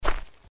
Gravel.mp3